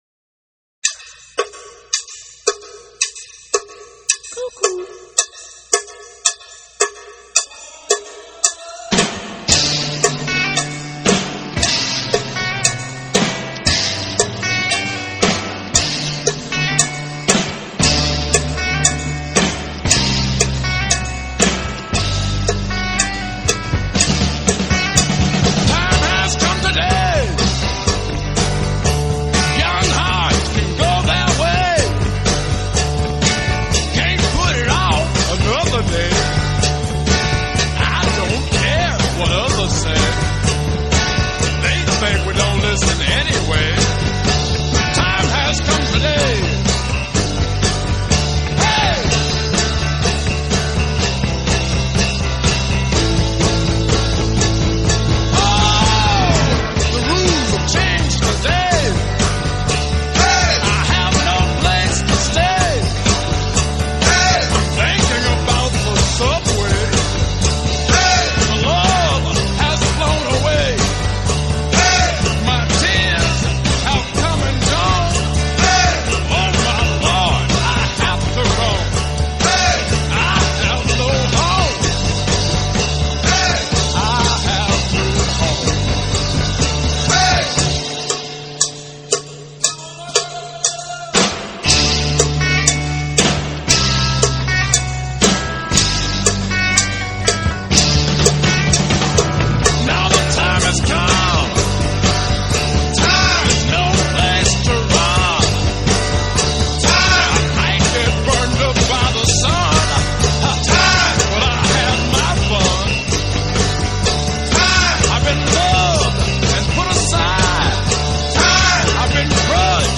با کیفیت صدای ریمسترشده از نوارهای اصلی 48-track